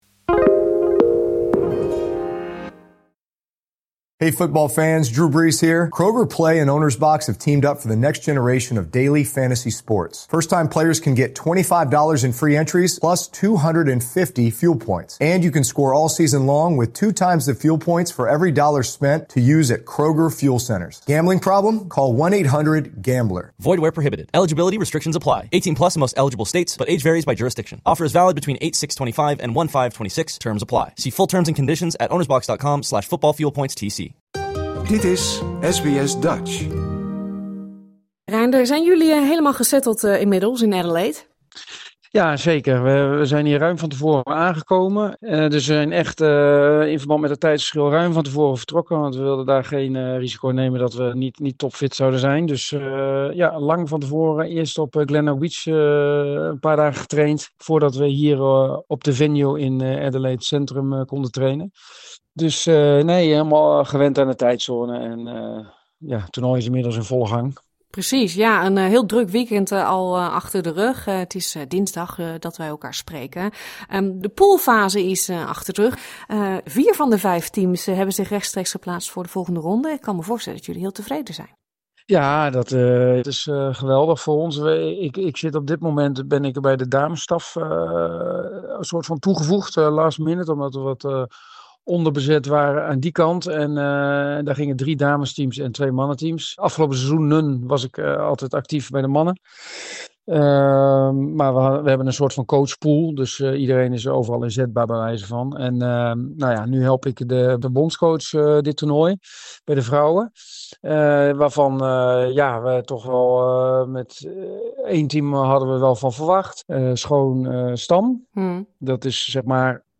In Adelaide (SA) doen vijf Nederlandse duo's mee aan het WK beachvolleybal. Wij bellen met assistent-bondscoach Reinder Nummerdor om te horen hoe het de teams vergaat en vroegen hem of Nederland serieus kans maakt op medailles.